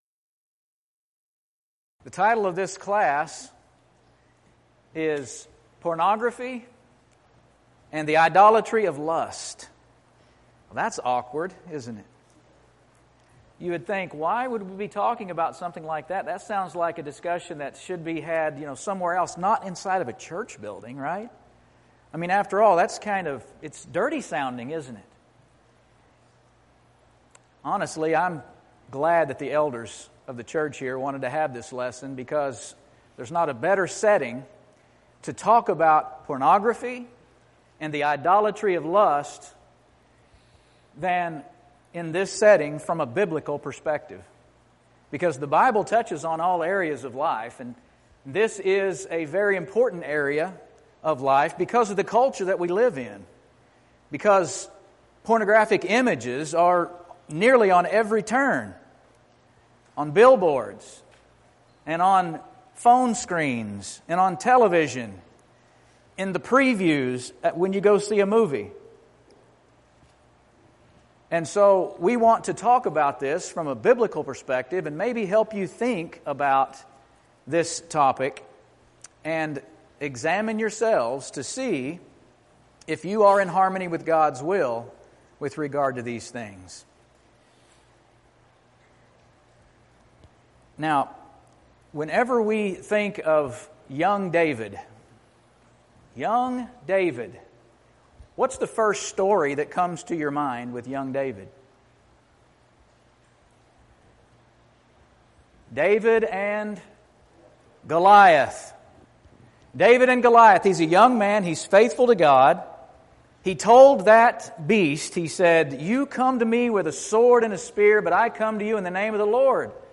Event: 2017 Discipleship University
Youth Sessions